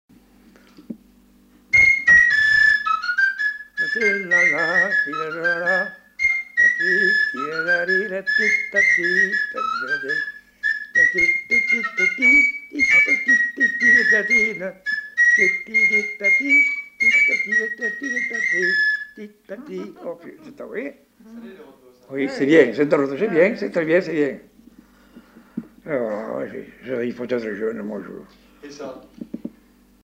Aire culturelle : Bazadais
Département : Gironde
Genre : morceau instrumental
Instrument de musique : flûte à trois trous
Danse : congo